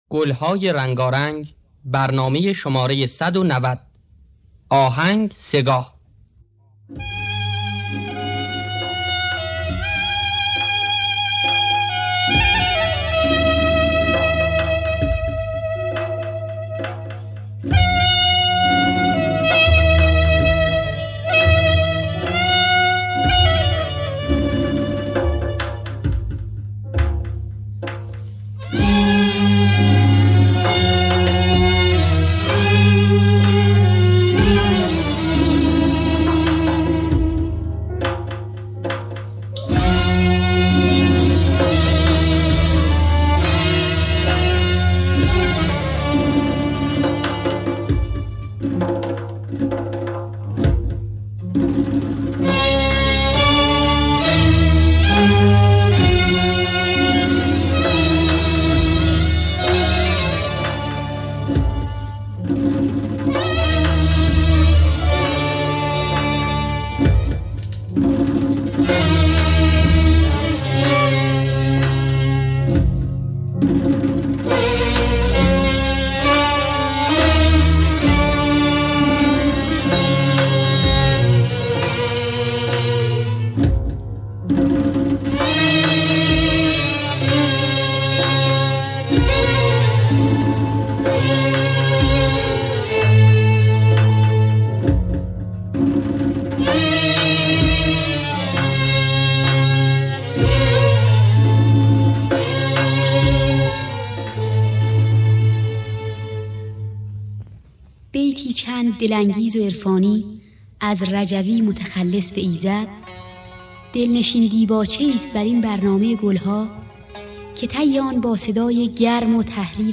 دانلود گلهای رنگارنگ ۱۹۰ با صدای بنان در دستگاه سه‌گاه. آرشیو کامل برنامه‌های رادیو ایران با کیفیت بالا.